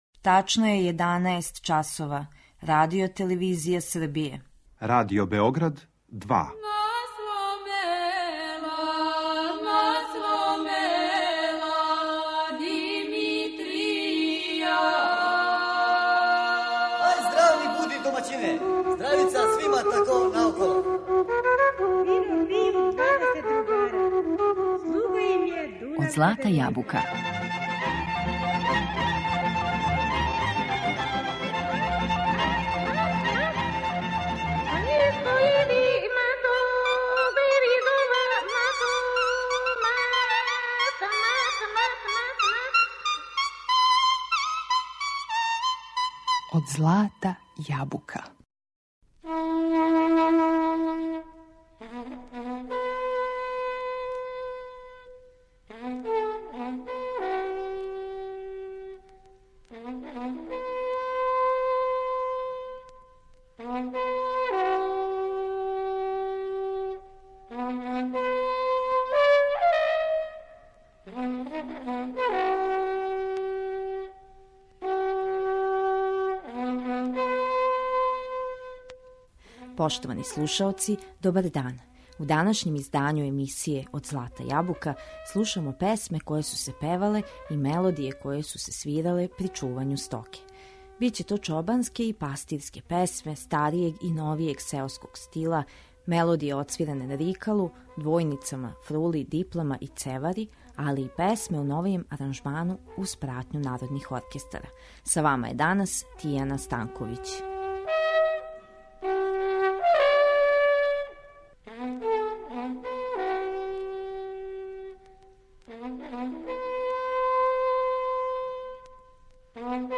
Биће то чобанске и пастирске песме старијег и новијег сеоског стила, мелодије одсвиране на рикалу, двојницама, фрули, диплама и цевари, као и песме у новијем аранжману уз пратњу народних оркестара.